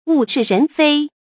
物是人非 注音： ㄨˋ ㄕㄧˋ ㄖㄣˊ ㄈㄟ 讀音讀法： 意思解釋： 東西還是原來的東西，可是人已不是原來的人了。